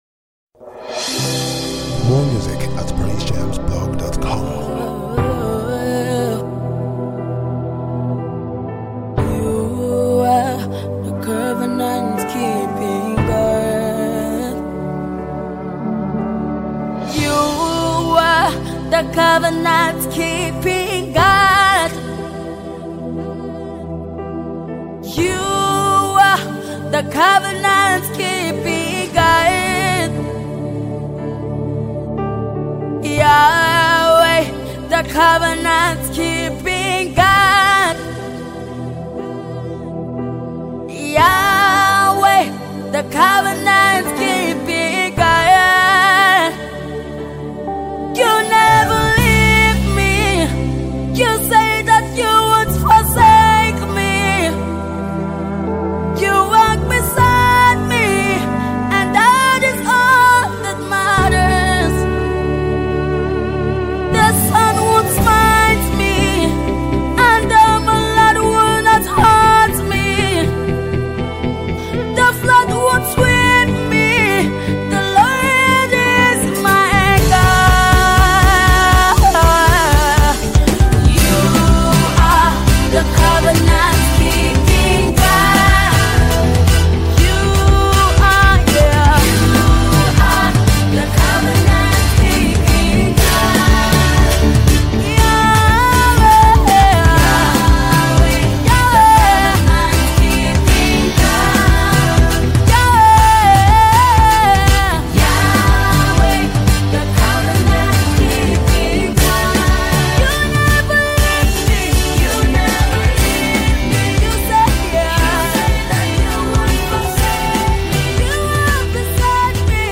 Nigerian multi-talented gospel music group/ministry